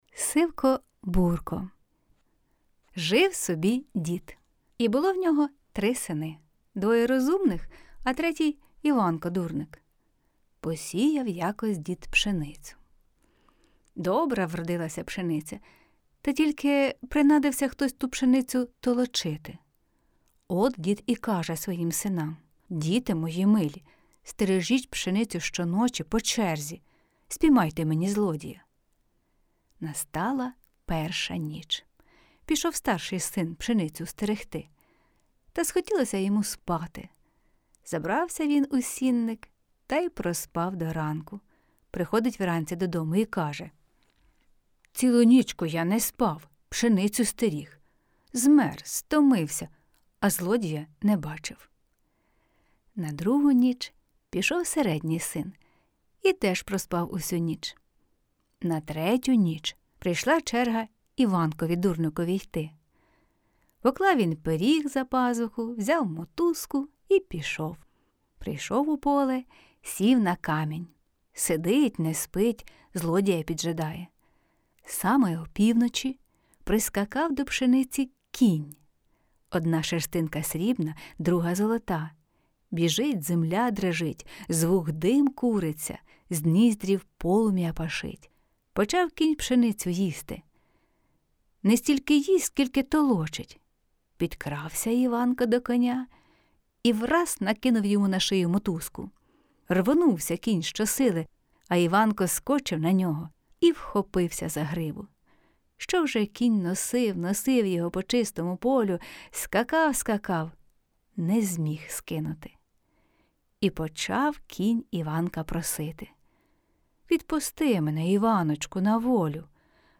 Жанр: Казка на добраніч Автор